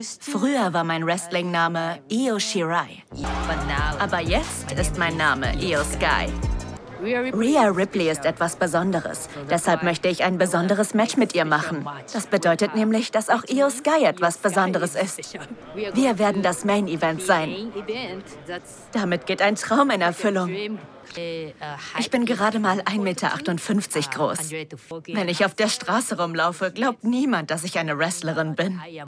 hell, fein, zart, sehr variabel, markant
Jung (18-30)
Off, Doku